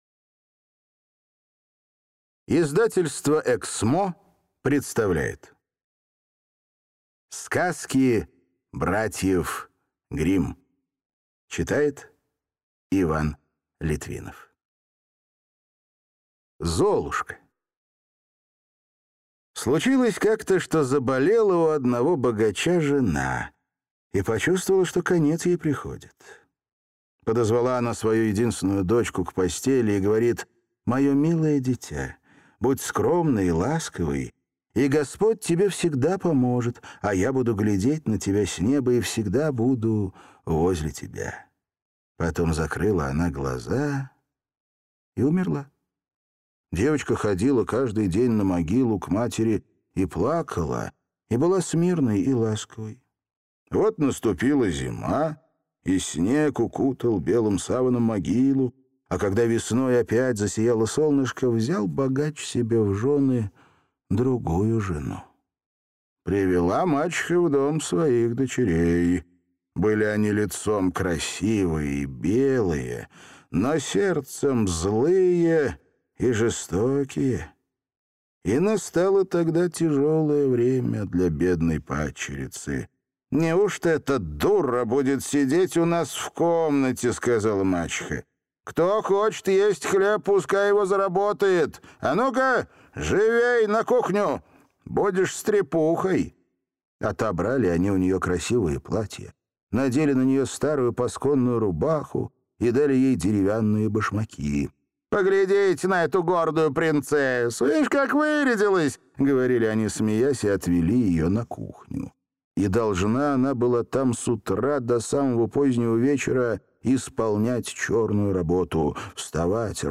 Аудиокнига Cамые красивые сказки братьев Гримм | Библиотека аудиокниг